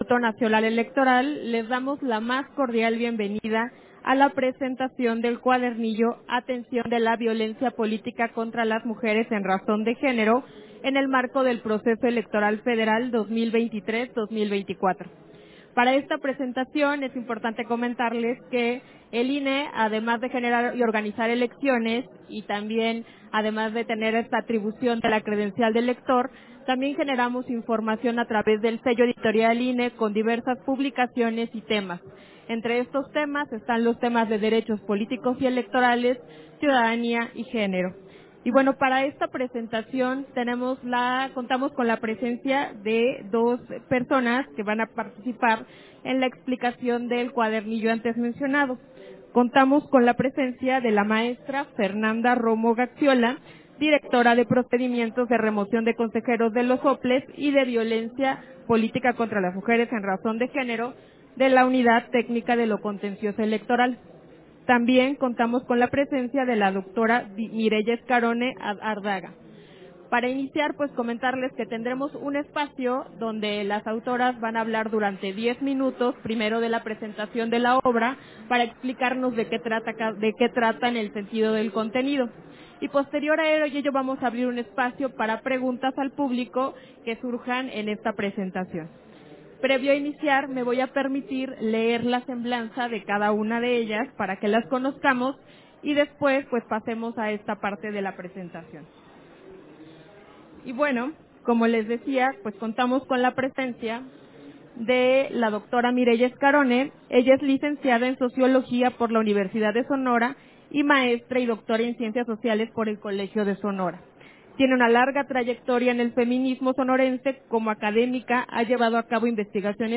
Versión estenográfica de la conferencia alusiva al folleto, Atención de la violencia políitica contra las mujeres en razón de género en el marco dle Proceso electoral Federal 2023-2024, FIL Guadalajara 2024